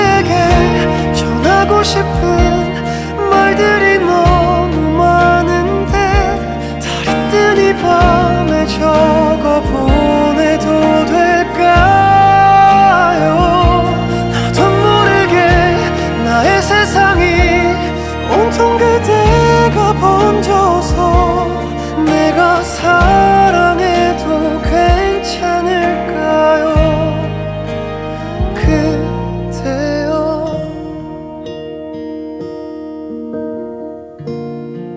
Жанр: Музыка из фильмов / Саундтреки